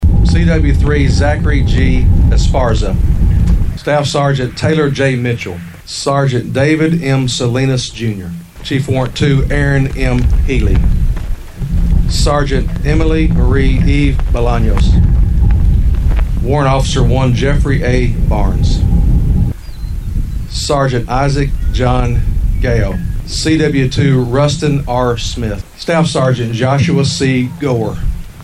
Trigg County Judge-Executive Stan Humphries read the names of the fallen aloud, as yellow roses were handed to the Gold Star families in attendance — many of which came from hundreds of miles away to share their grief, and find comfort through others.